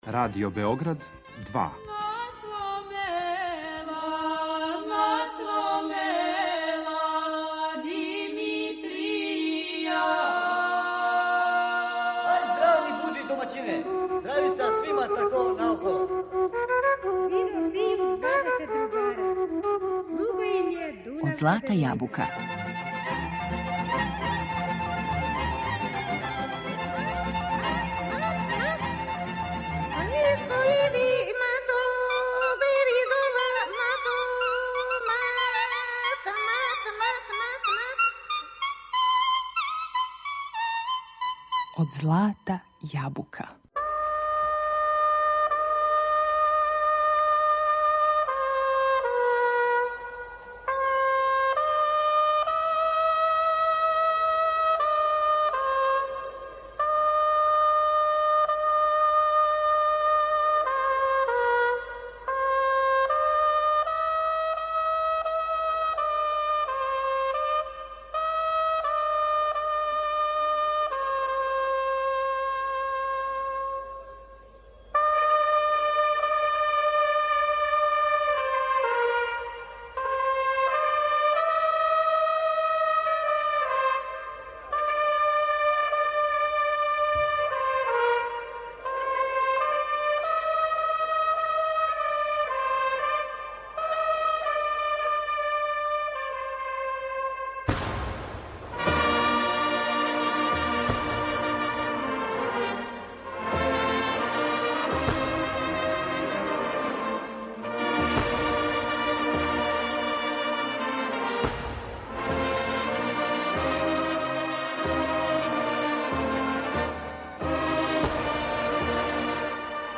Данас слушамо прангије, клепетала, клепетуше, звона и друге несвакидашње звуке.
Уживаћемо и у снимцима народних кола нашег ансамбла „Ренесанс".